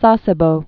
(säsĕ-bō)